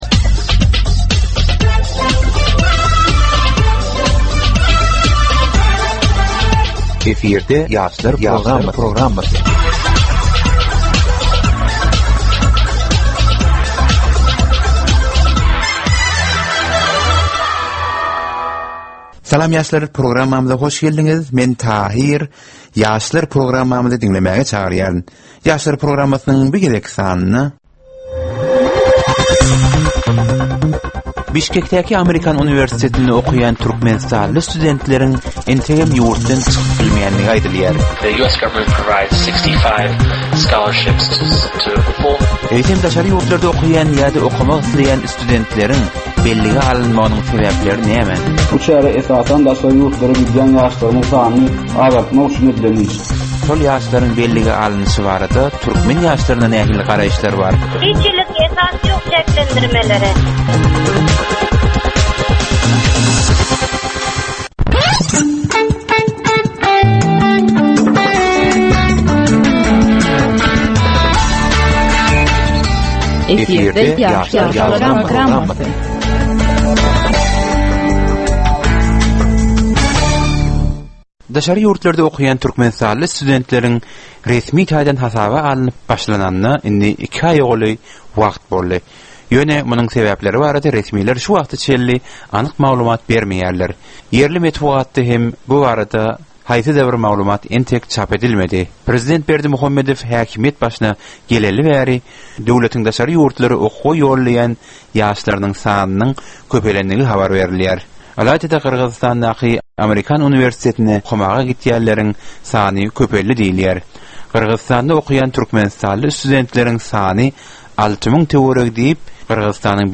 Türkmen we halkara yaslarynyn durmusyna degisli derwaýys meselelere we täzeliklere bagyslanylyp taýýarlanylýan 15 minutlyk ýörite geplesik. Bu geplesiklde ýaslaryn durmusyna degisli dürli täzelikler we derwaýys meseleler barada maglumatlar, synlar, bu meseleler boýunça adaty ýaslaryn, synçylaryn we bilermenlerin pikrileri, teklipleri we diskussiýalary berilýär. Geplesigin dowmynda aýdym-sazlar hem esitdirilýär.